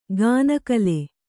♪ gāna kale